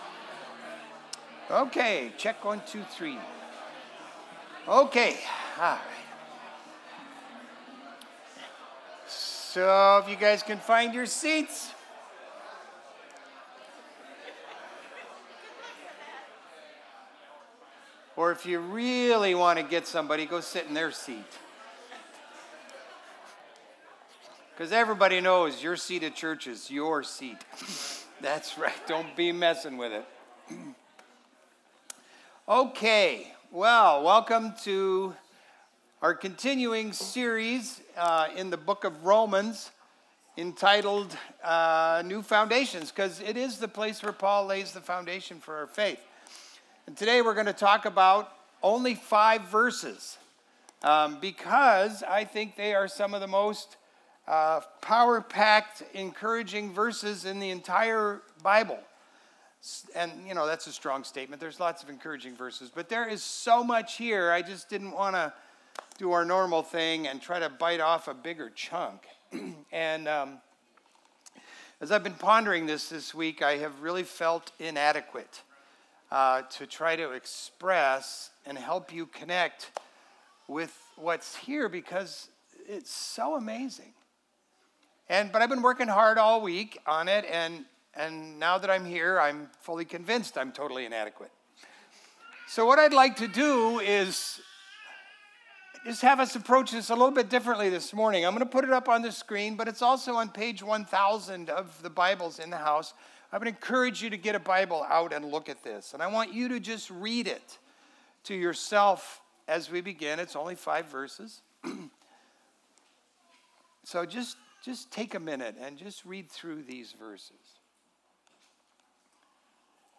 Download Audio Home Resources Sermons The Hope of Righteousness Jul 13 The Hope of Righteousness The blessings that flow into our lives from God’s righteousness bring hope for now and the future.